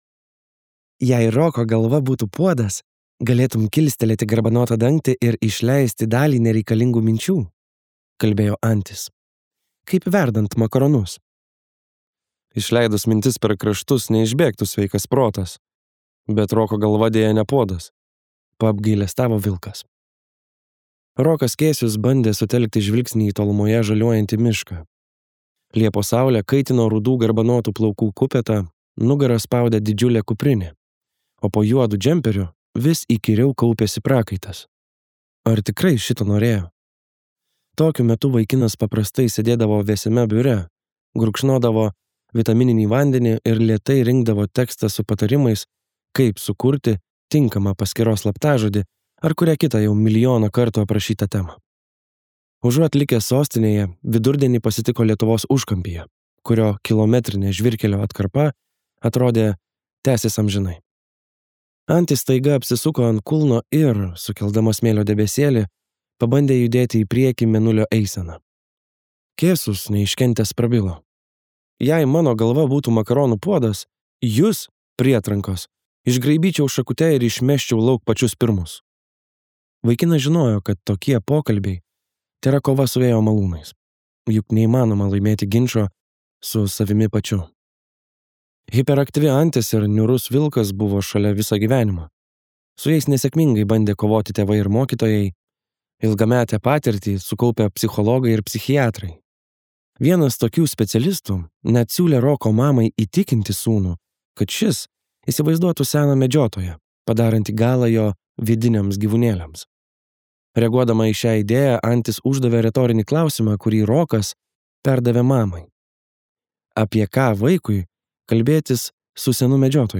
Kosto Strielkūno audioknyga „Mieste nebetiki velniais“ – tai lietuviškas detektyvas, kuriame susipina kaimo gyvenimo ypatumai, mitologija ir angliško detektyvo pasakojimo stilistika.